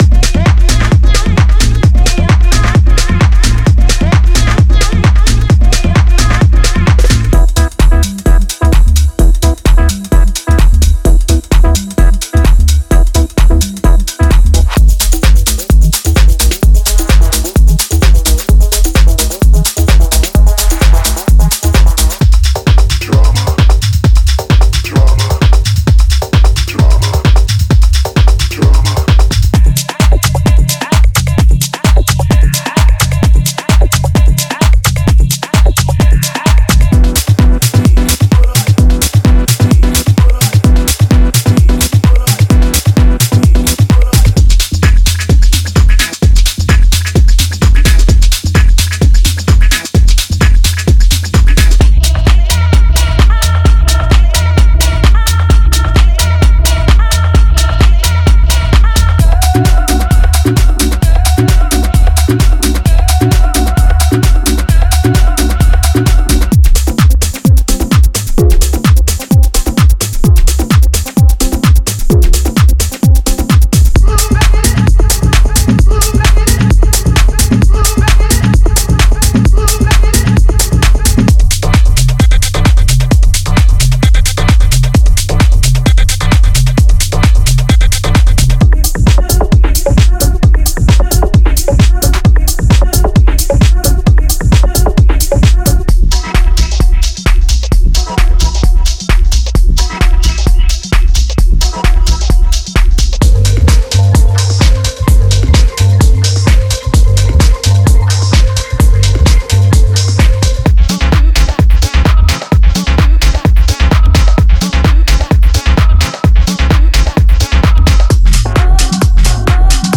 デモサウンドはコチラ↓
Genre:Tech House
150 Beat Loops